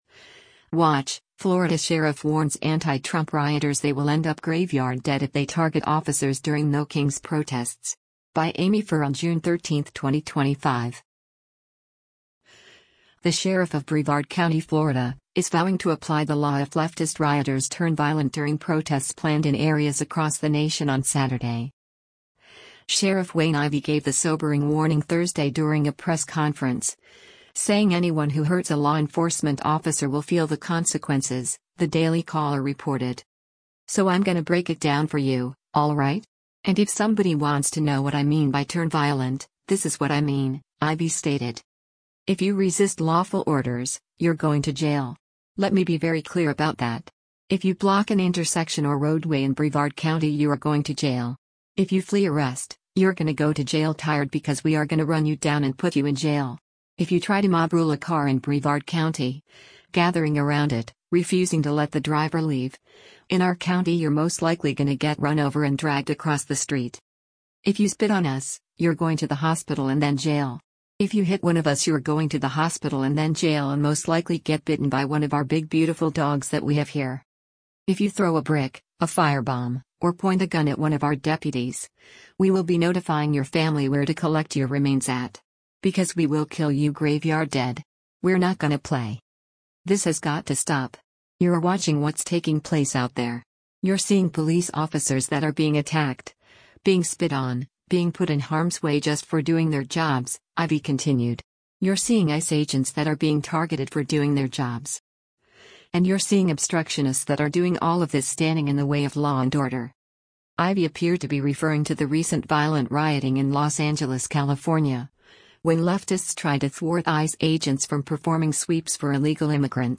Sheriff Wayne Ivey gave the sobering warning Thursday during a press conference, saying anyone who hurts a law enforcement officer will feel the consequences, the Daily Caller reported.